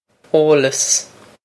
eolas oh-loss
This is an approximate phonetic pronunciation of the phrase.